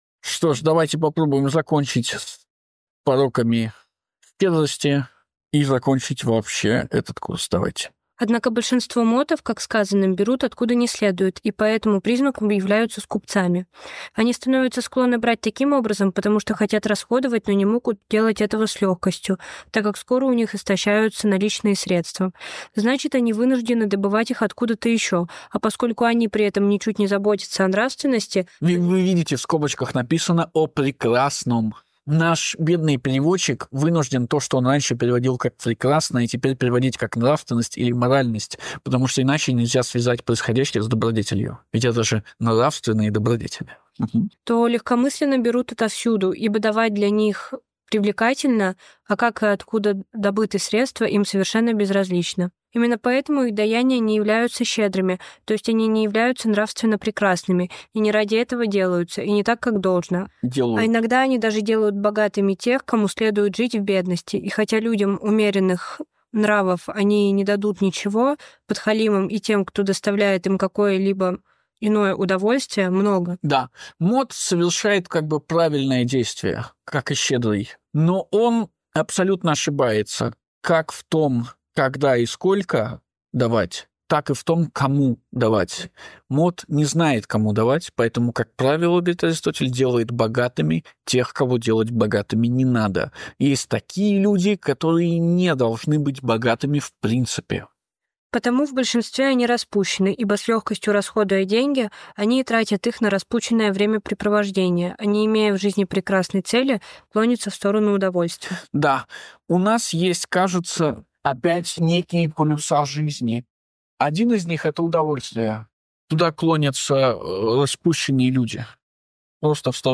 Перед вами завершение очередного сезон проекта "Совместные чтения", состоявшегося на базе Государственного академического университета гуманитарных наук в 2020 году.